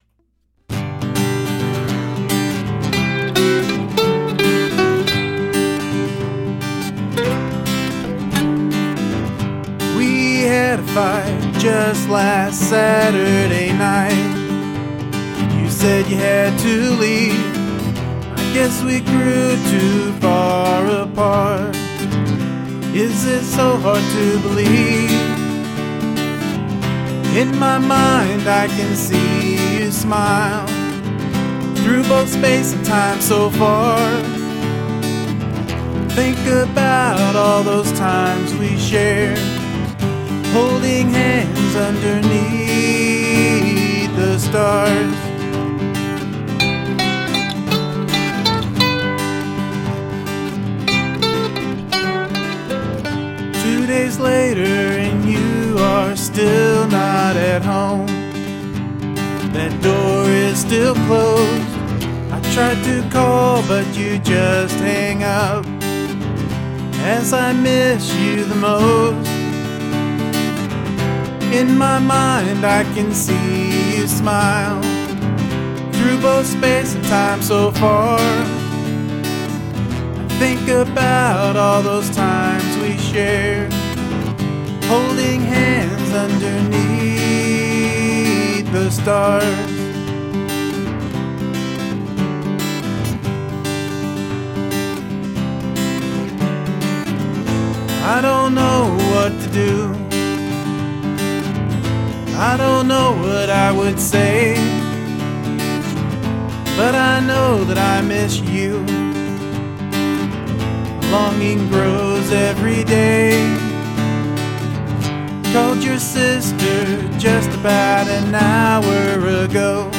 Cool idea for a sticky sweet love song.... but I kinda turned it into the opposite.
I like it the ways it is though, I like the chord progression and the vocals.
very beutiful guitar. and well excecuted.
Has a real Green day Vibe to it!!!!!
This song has a catchy melody.